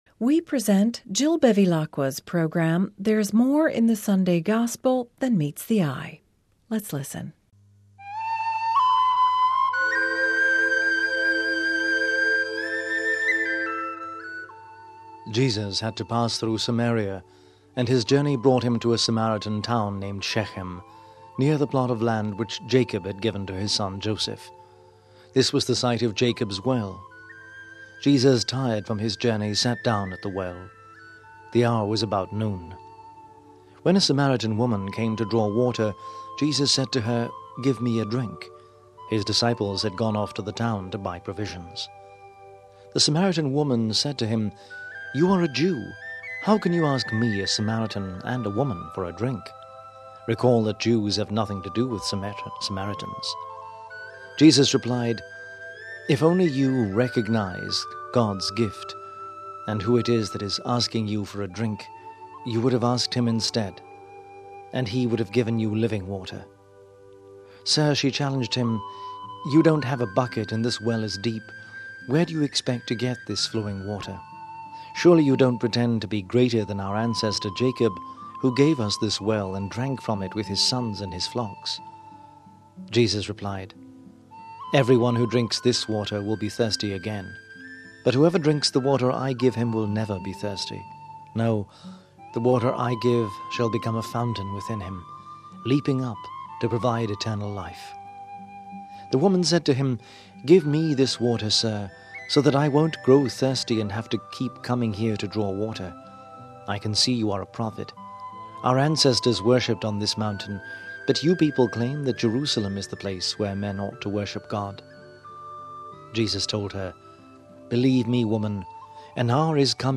readings and reflections for the Third Sunday of Lent